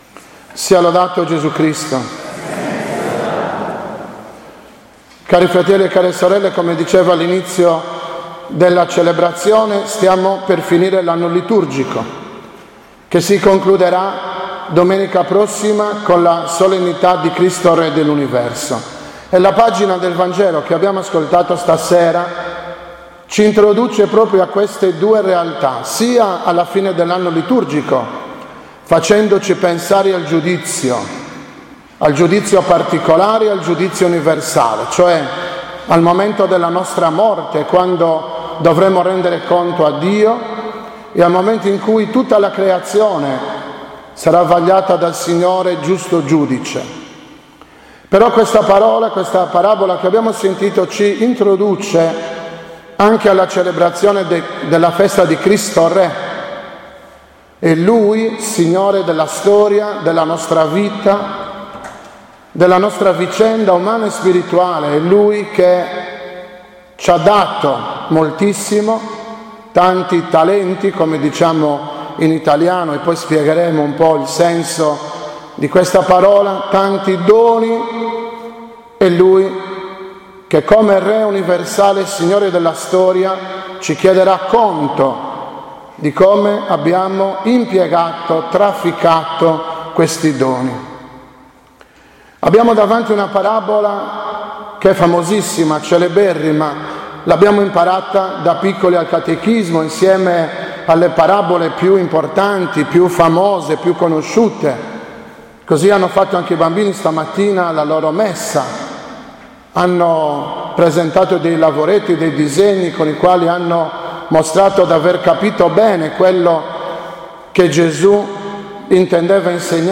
16.11.2014 – OMELIA DELLA XXXIII DOMENICA DEL TEMPO ORDINARIO